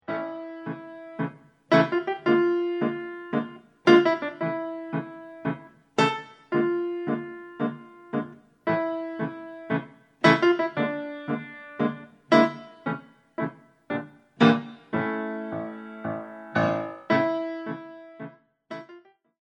All piano CD for Pre- Ballet classes.